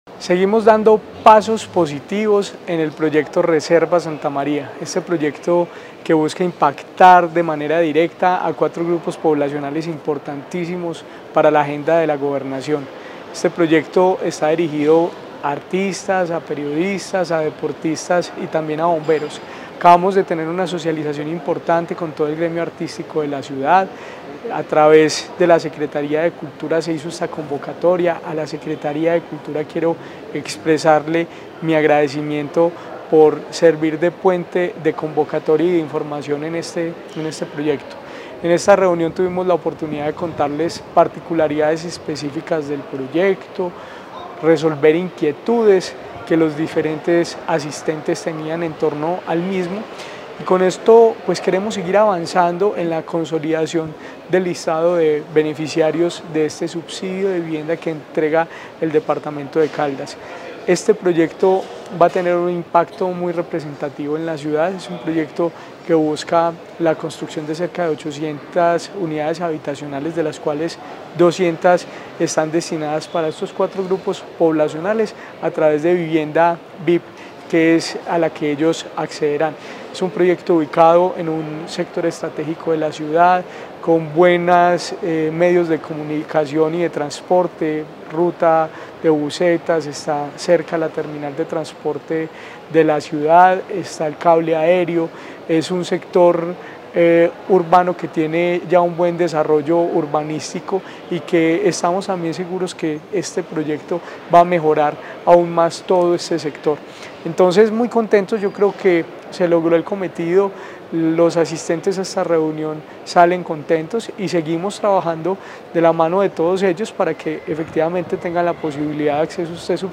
Jorge William Ruiz Ospina, secretario de Vivienda y Territorio de Caldas.